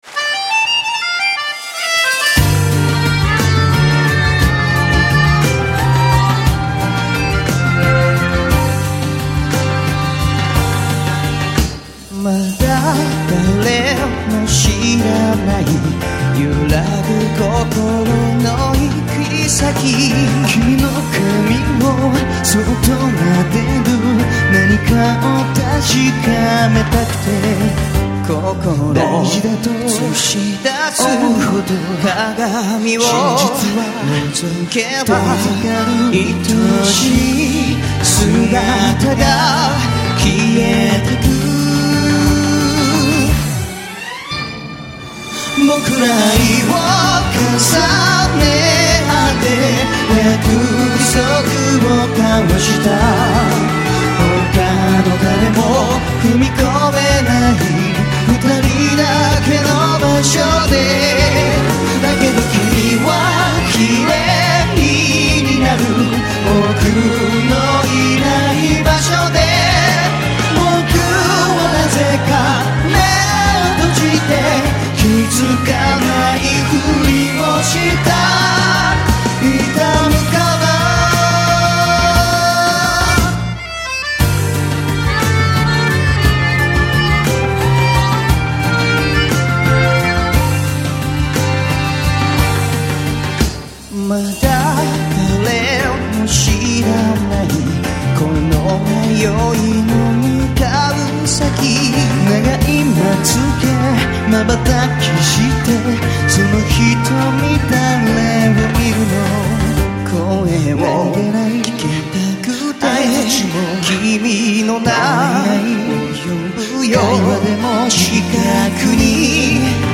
완전 두 사람의 그..... 눈물을 머금은 목소리에 깊디깊은 호소력이 절절 철철 콸콸 !!!ㅠㅠ